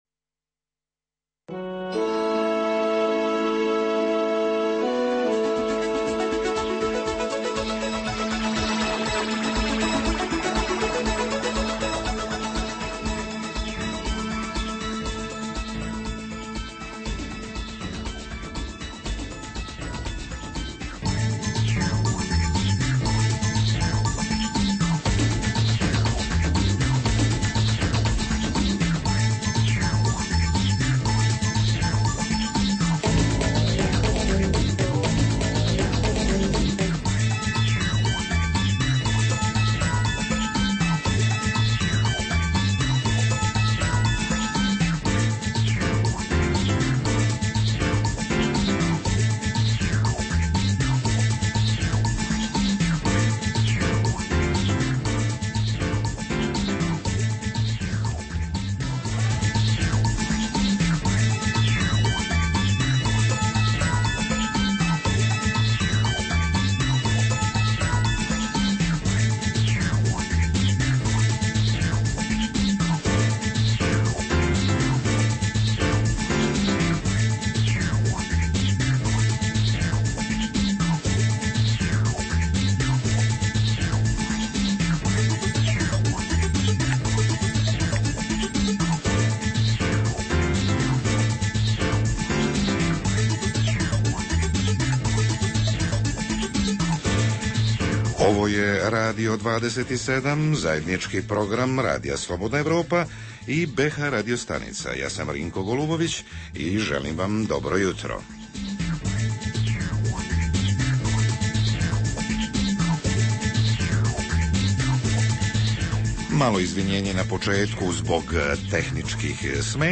Jutarnji program za BiH koji se emituje uživo. U emisiji, osim aktuelnih događaja dana, tražimo koji je to bosansko-hercegovački turistički potez ili projekat godine.